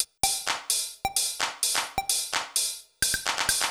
TEC Beat - Mix 10.wav